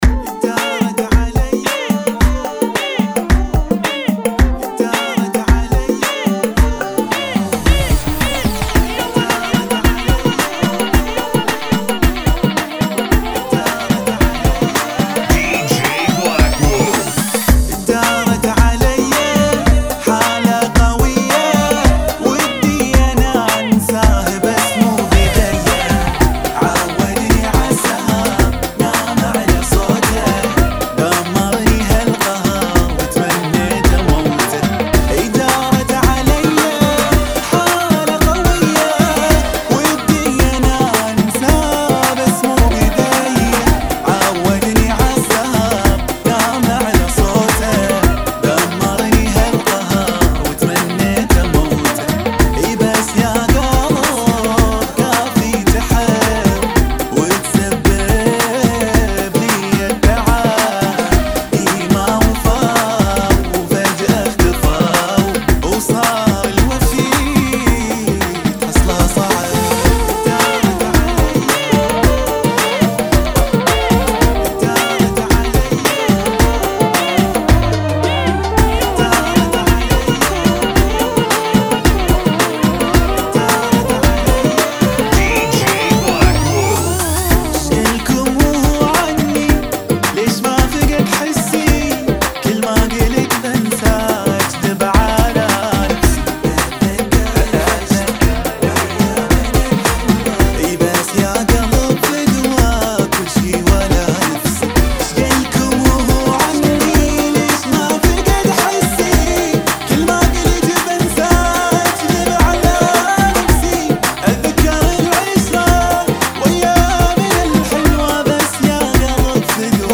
110 Bpm Funky Remix